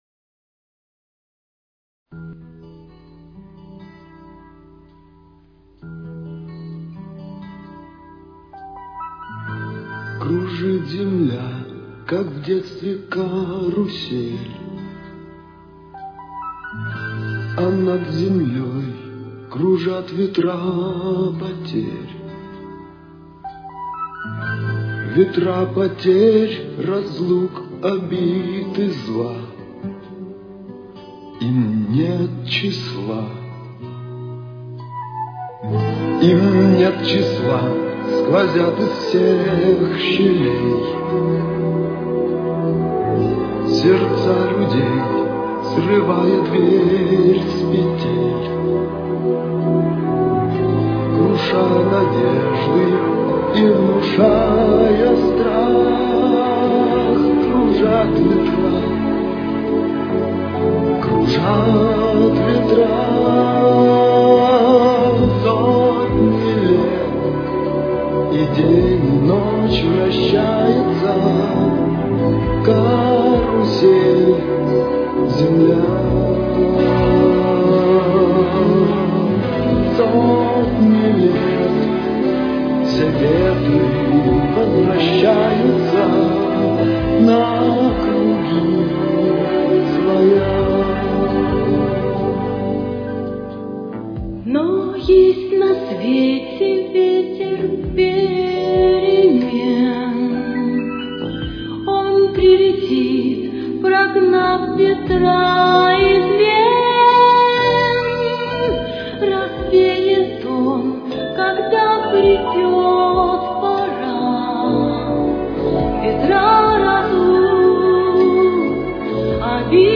с очень низким качеством (16 – 32 кБит/с)
Темп: 67.